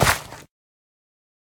Minecraft Version Minecraft Version latest Latest Release | Latest Snapshot latest / assets / minecraft / sounds / block / suspicious_gravel / step1.ogg Compare With Compare With Latest Release | Latest Snapshot
step1.ogg